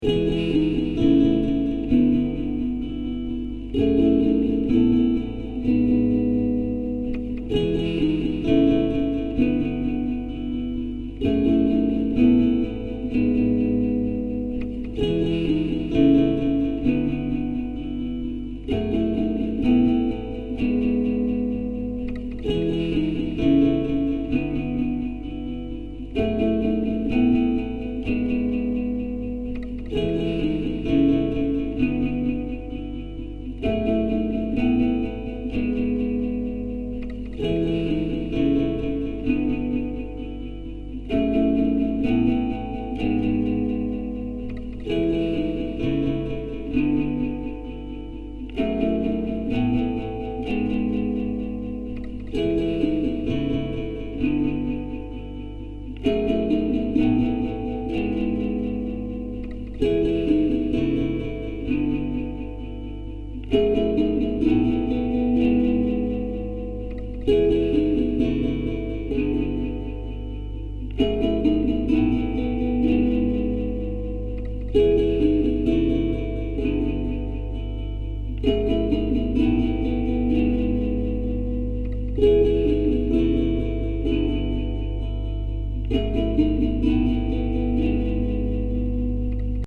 Recorded Bday 2010, Clips made August in Calais
Guitar_01_3_12bars.mp3